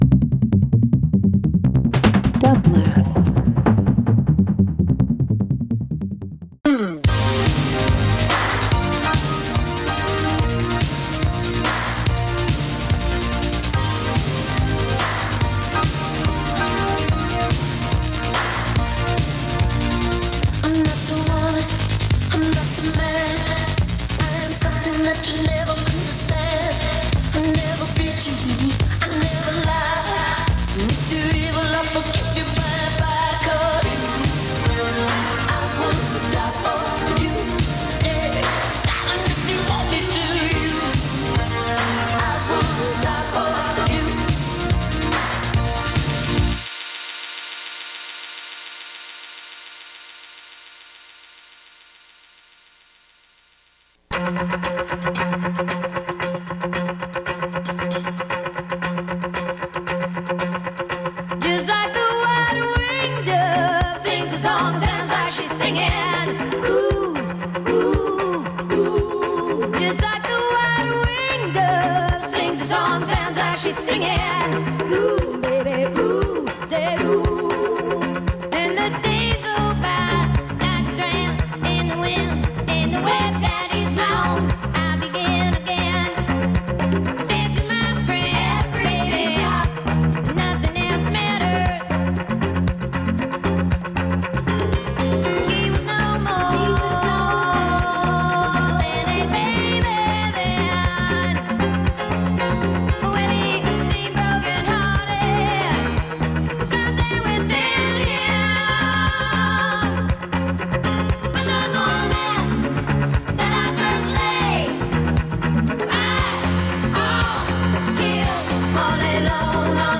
Oldies Pop Rock Synth